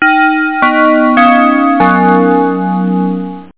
GrandfatherClock.mp3